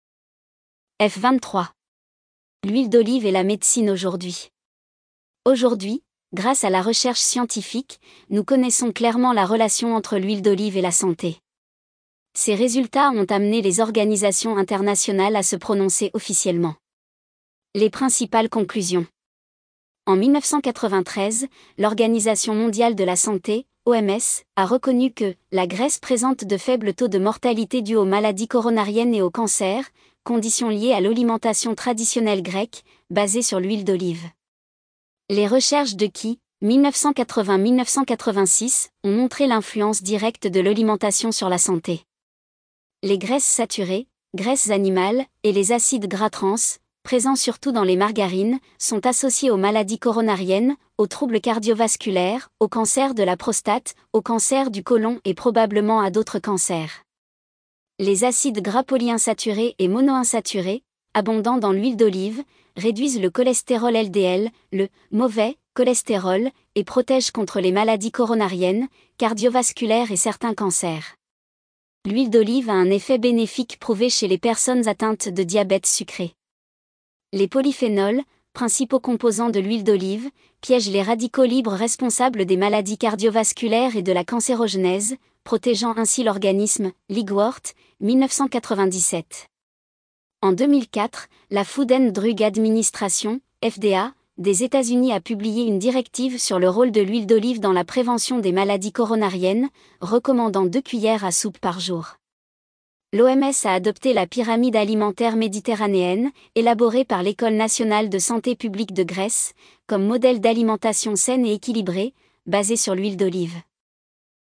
Visite guidée audio